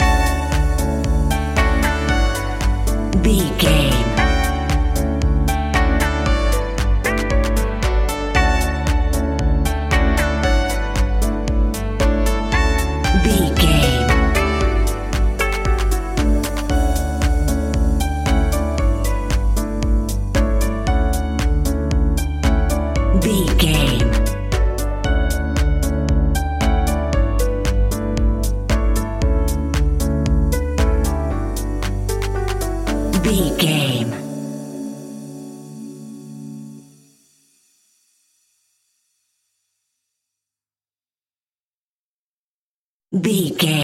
Ionian/Major
D
uplifting
energetic
bouncy
electric piano
drum machine
synthesiser
electro house
house instrumentals
synth leads
synth bass